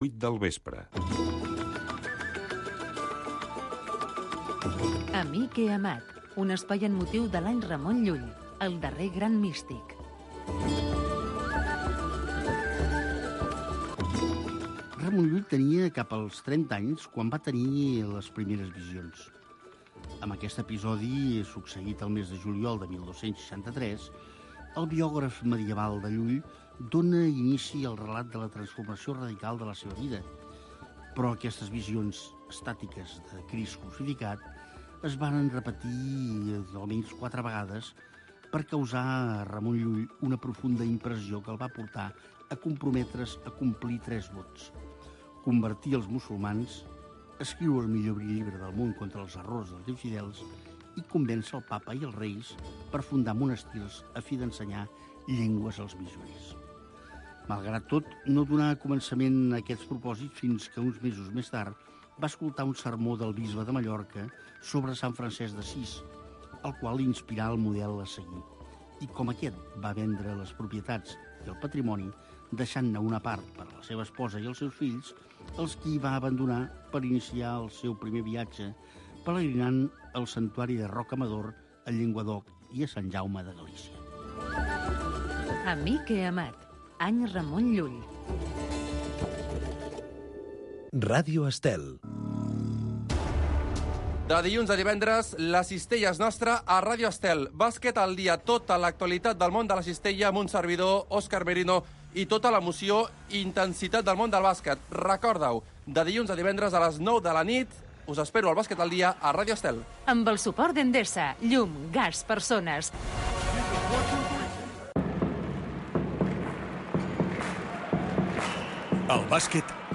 Un programa amb entrevistes i tertúlia sobre la família amb clau de valors humans, produït pel l'associació FERT.